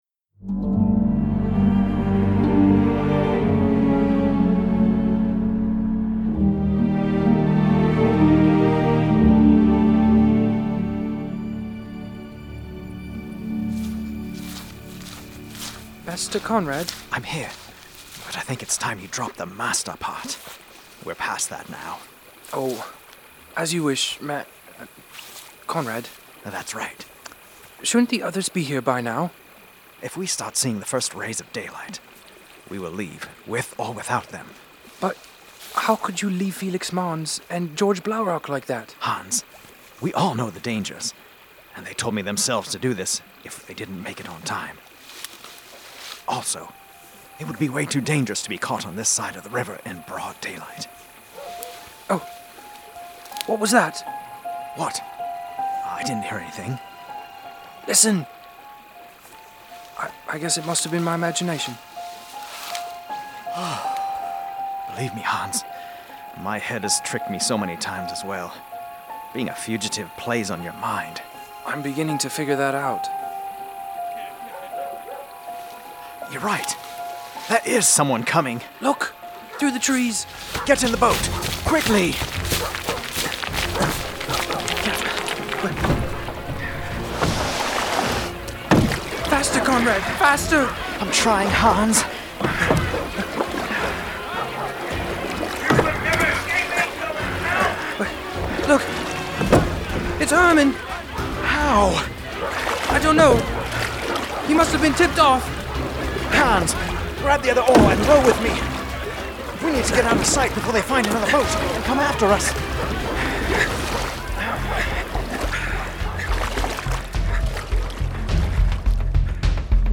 This story follows the first hand perspectives of the early Anabaptists in January 1525. Our hope for this drama is to show a behind-the-scenes look into the trials that Anabaptist founders, like Conrad Grebel, could have faced.